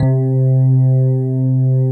Index of /90_sSampleCDs/USB Soundscan vol.09 - Keyboards Old School [AKAI] 1CD/Partition A/13-FM ELP 1